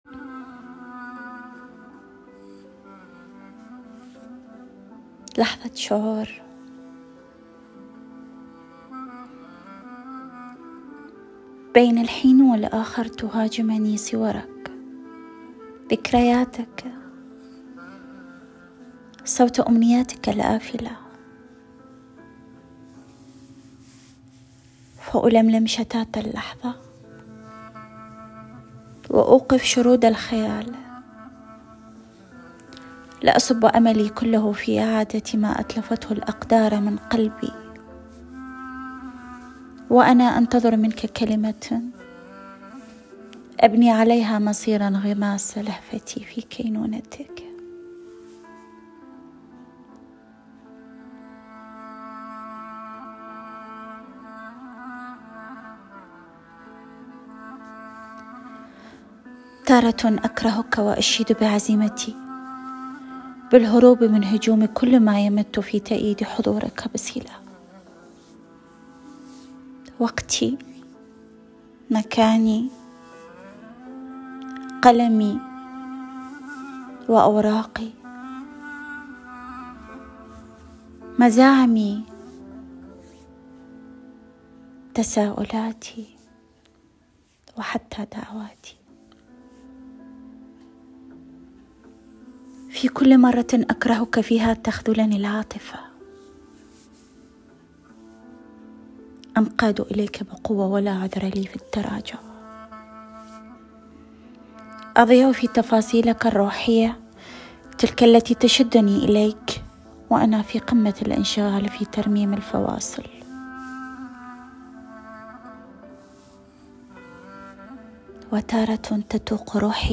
الموسيقى المصاحبه ،كانت مدهشه بصوت الابوا والبيانو معا.